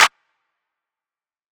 Clap (ImUpset).wav